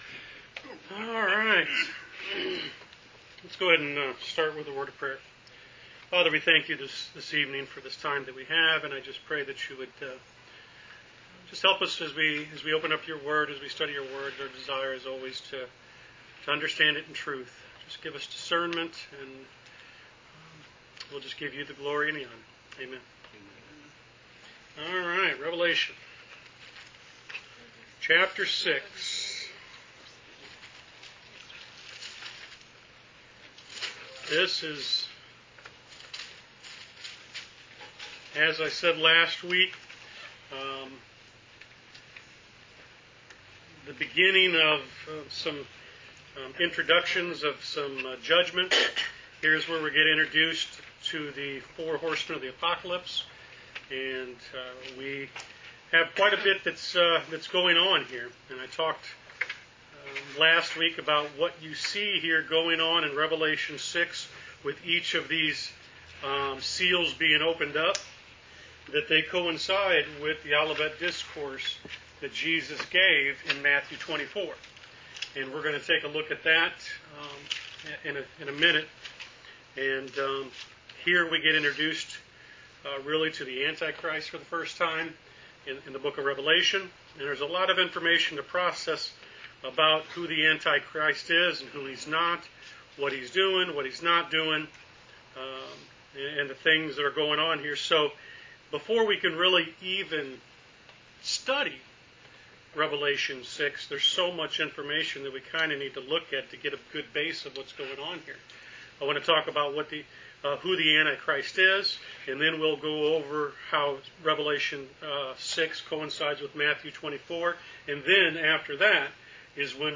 Wednesday Bible Study: Rev Ch 6